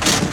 1 channel
krach1.wav